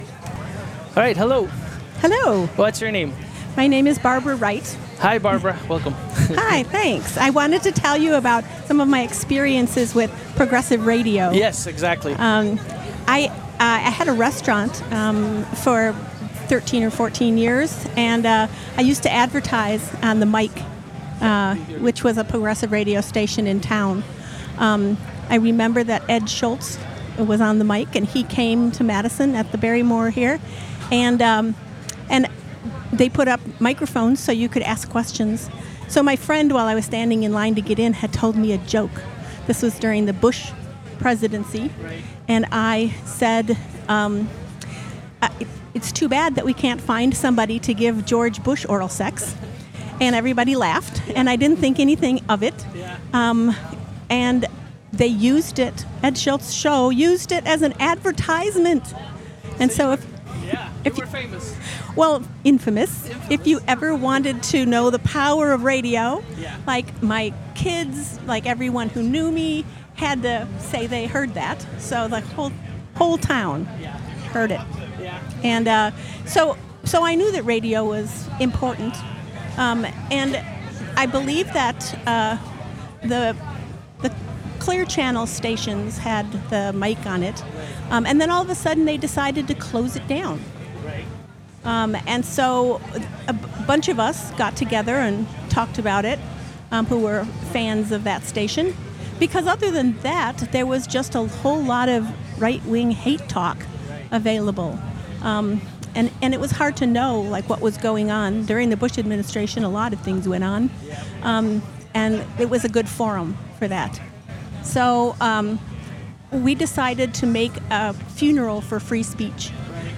We're excited to share the full interview!